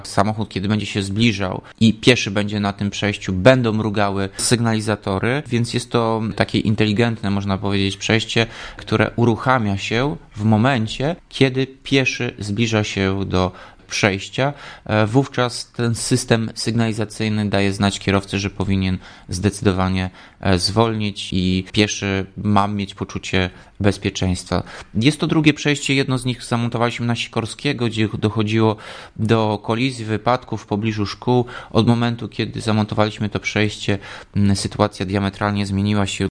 Koszt budowy aktywnego przejścia dla pieszych to blisko 70 tys zł. Umowa z wykonawcą została podpisana i wkrótce rozpoczną się prace, mówi Tomasz Andrukiewicz prezydent Ełku.